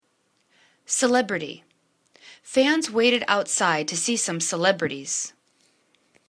ce.leb.ri.ty    /sə'lebrəti/    n